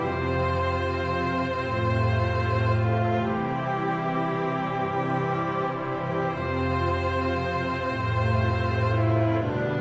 Garage Synth